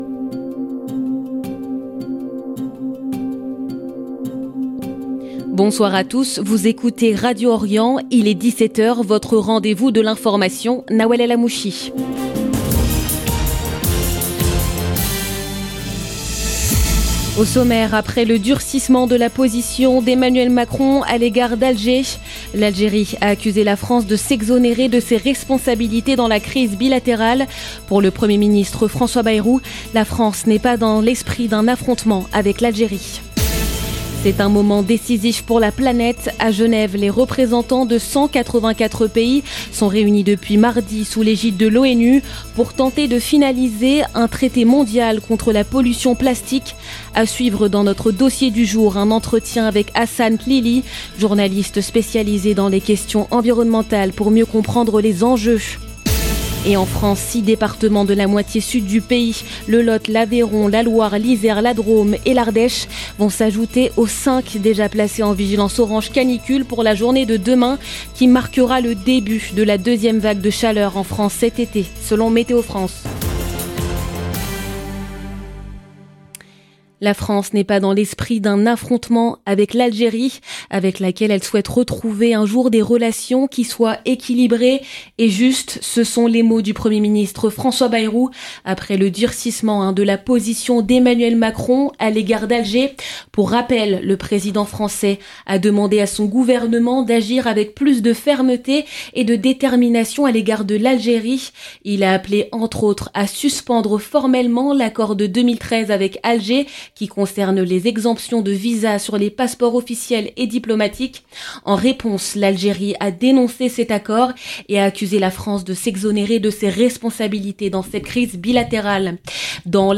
Magazine d'information du 7 août 2025